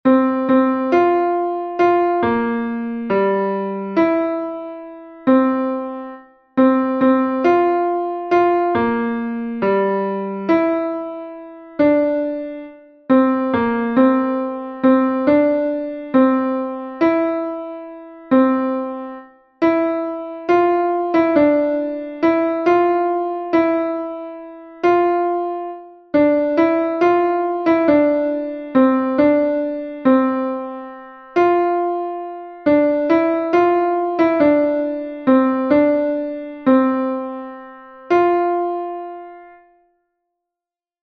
Fichier son alto 2